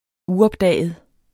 Udtale [ ˈuʌbˌdæˀjəð ]